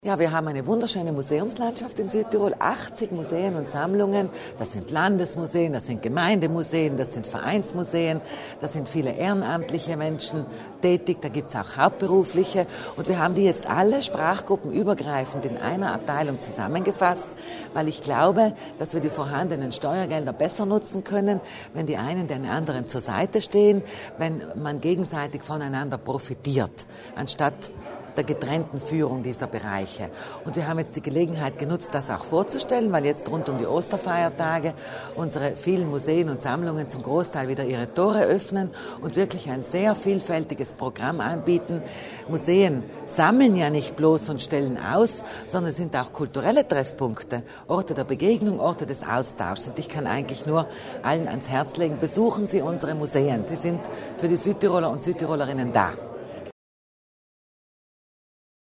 Landesrätin Kasslatter Mur erläutert das Projekt Abteilung Museen